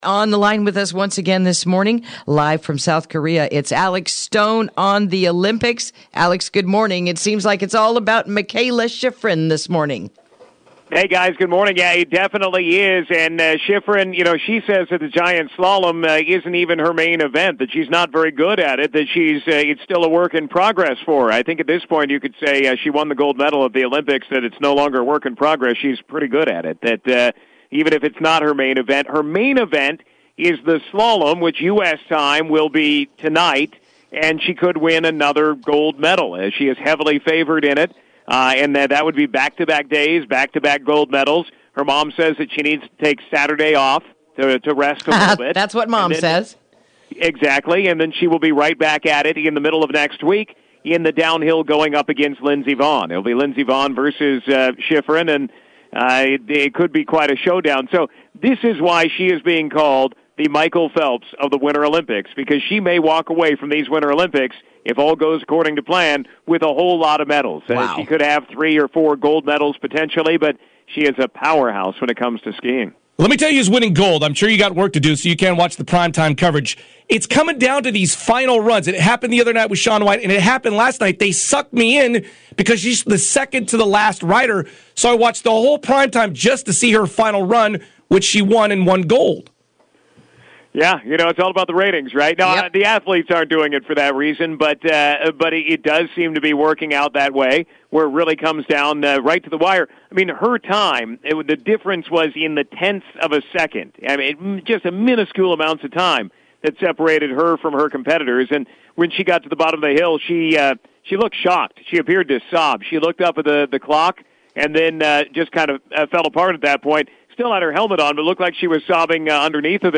Interview: Day 6 of the 2018 Winter Olympics